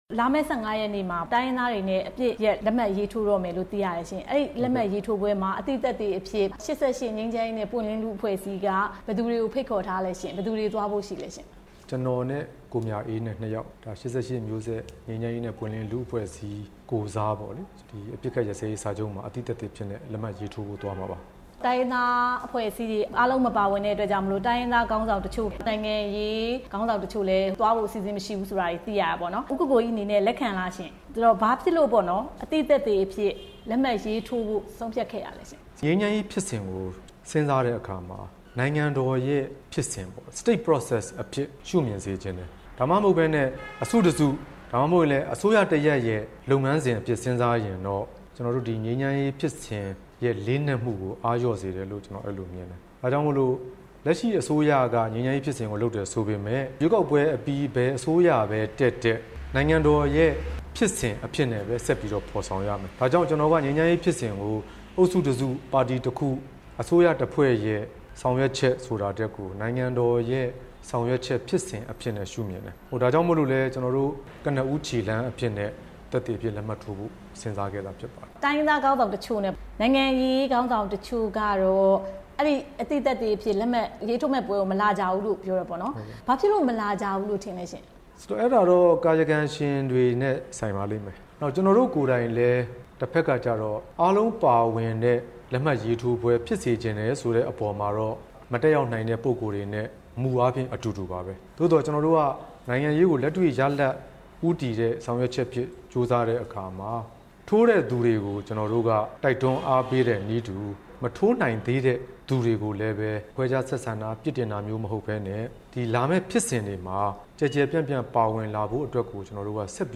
ဦးကိုကိုကြီးနဲ့ မေးမြန်းချက်